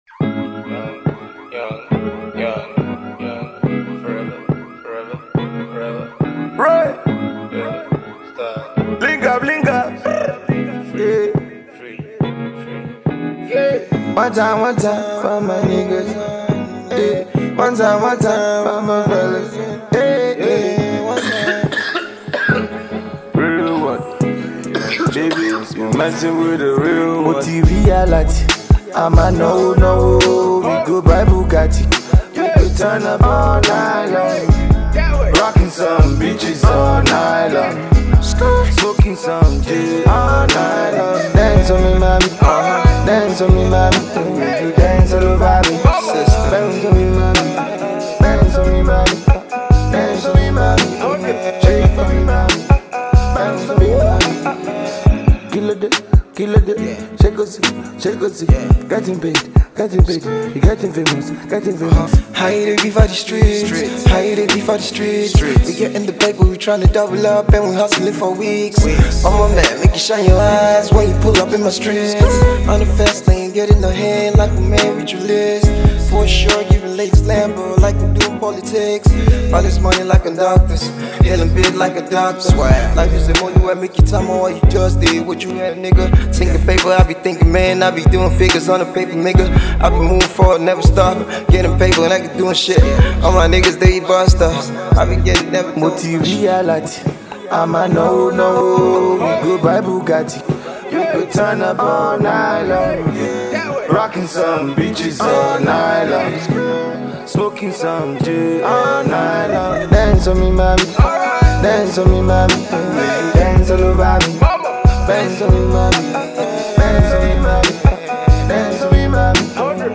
afro/trap inspired single